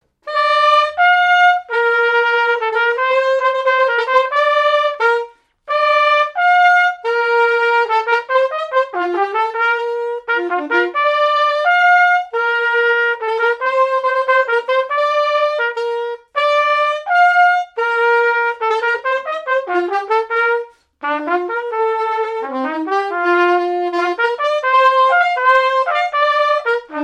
Marche nuptiale
Pièce musicale inédite